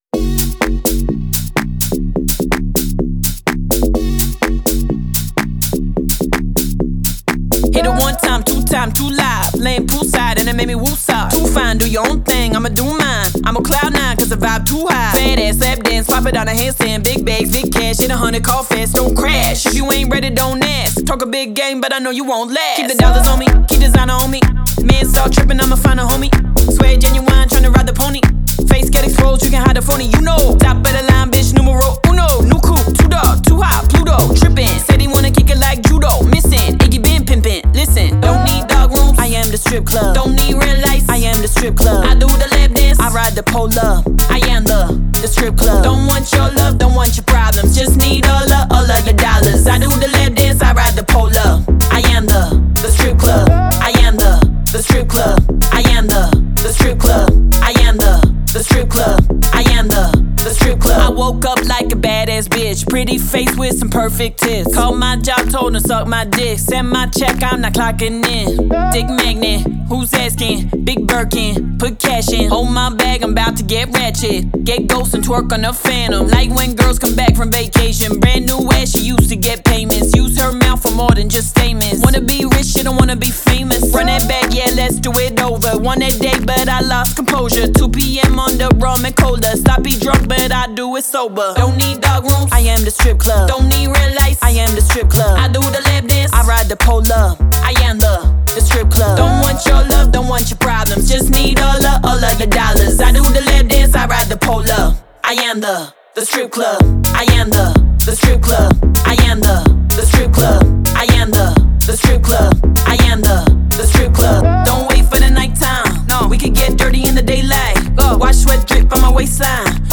это энергичная хип-хоп композиция